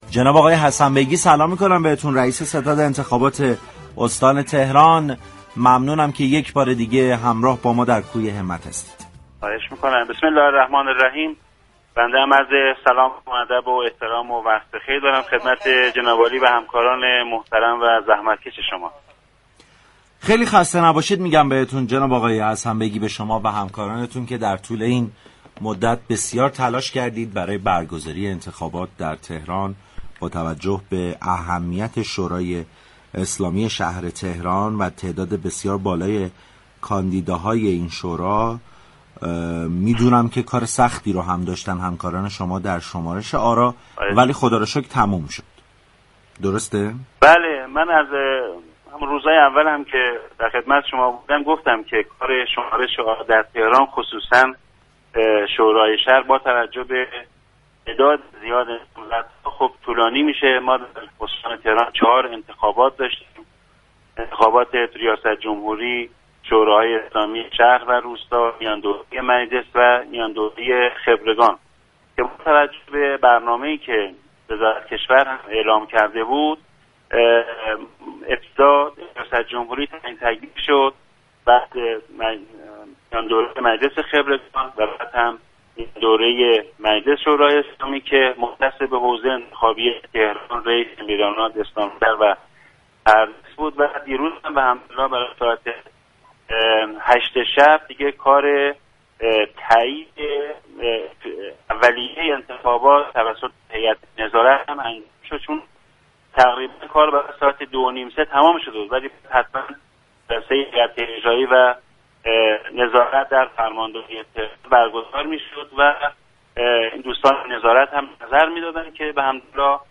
به گزارش پایگاه اطلاع رسانی رادیو تهران، شكرالله حسن بیگی، رئیس ستاد انتخابات استان تهران در گفتگو با برنامه انتخاباتی كوی همت نتایج نهایی انتخابات شورای شهر تهران را اعلام كرد.